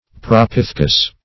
Search Result for " propithecus" : The Collaborative International Dictionary of English v.0.48: Propithecus \Prop`i*the"cus\, n. [NL., fr. Gr.